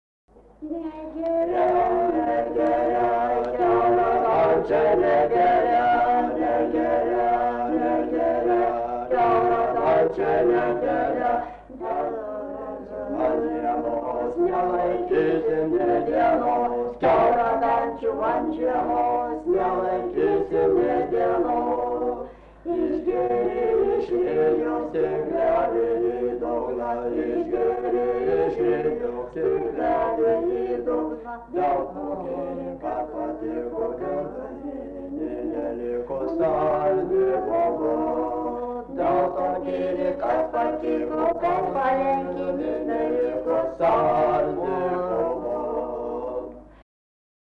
vaišių daina